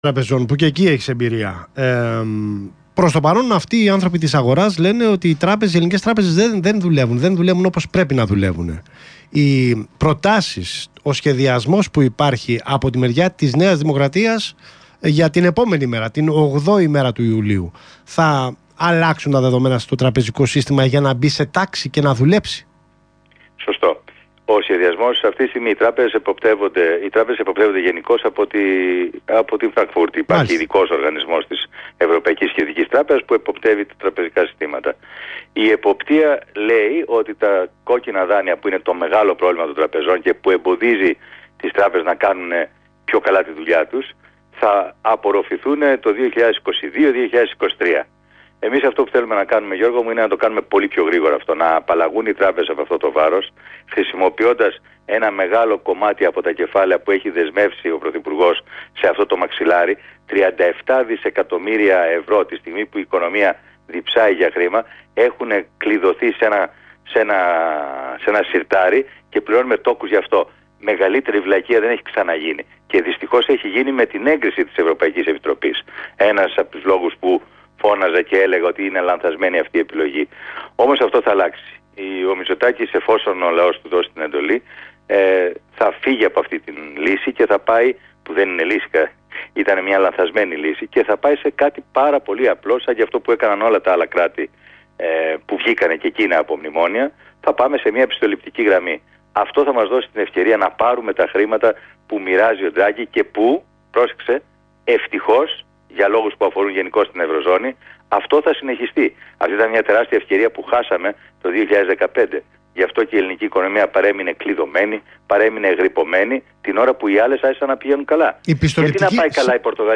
Ο Μπ. Παπαδημητρίου μίλησε στο ραδιόφωνο του Real και είπε: «Ο Μητσοτάκης, εφόσον ο λαός του δώσει την εντολή, θα φύγει από αυτή τη λύση και θα πάει σε κάτι πολύ απλό, σαν και αυτό που έκαναν τα υπόλοιπα κράτη που βγήκαν από μνημόνια, θα πάμε σε μια πιστοληπτική γραμμή».